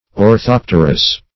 Orthopterous \Or*thop"ter*ous\, a. (Zool.) Of or pertaining to the Orthoptera .